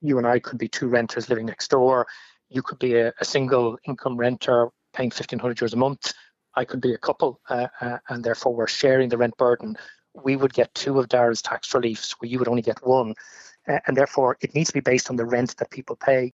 But Sinn Féin’s Housing Spokesperson, Eoin Ó Broin believes this is completely unfair on some: